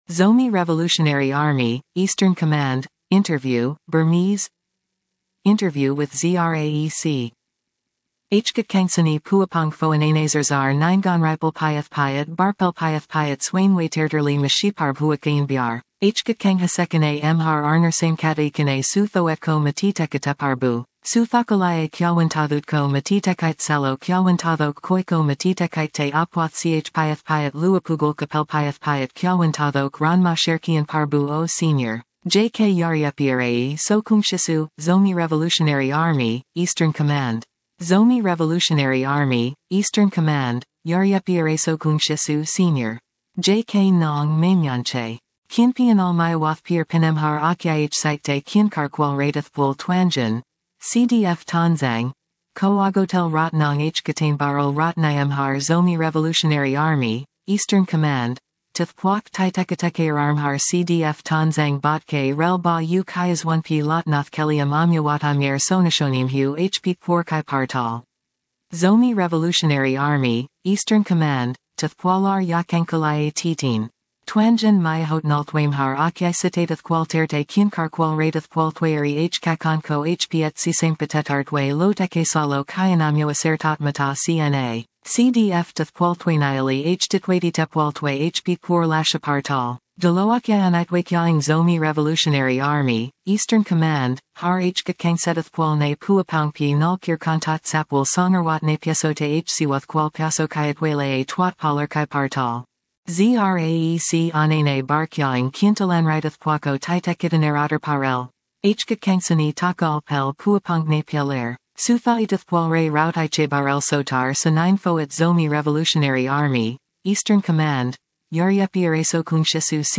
Zomi Revolutionary Army (Eastern Command) Interview (Burmese) - Zomi Press